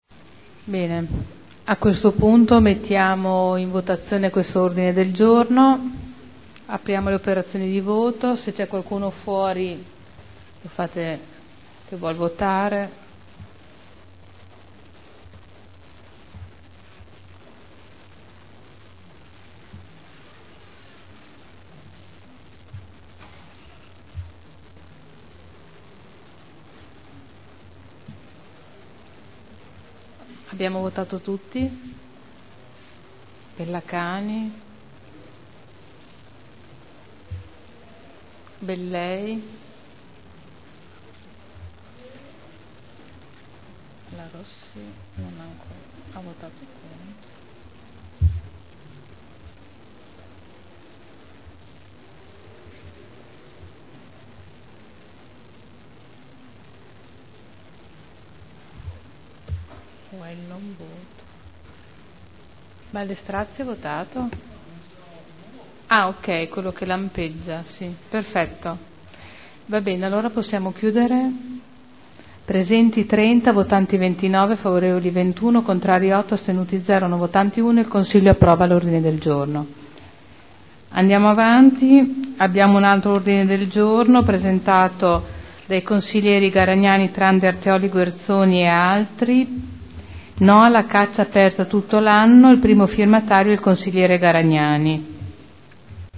Presidente — Sito Audio Consiglio Comunale